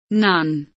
none kelimesinin anlamı, resimli anlatımı ve sesli okunuşu